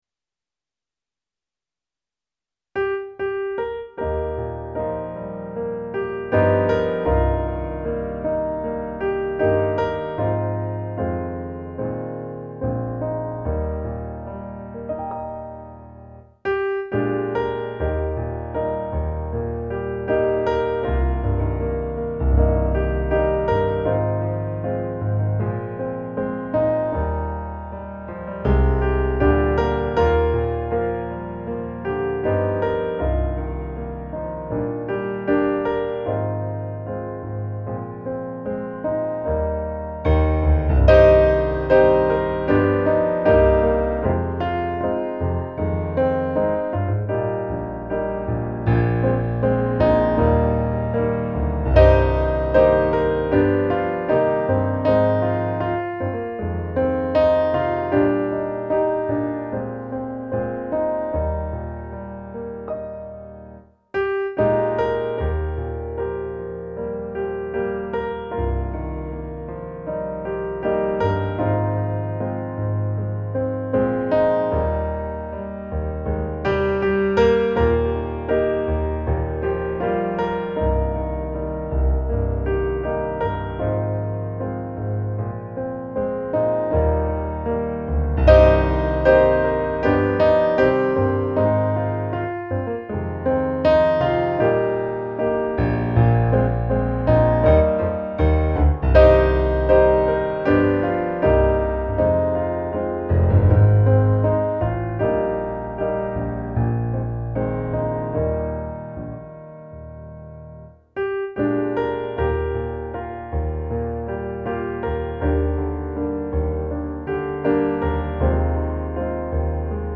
Musikbakgrund Psalm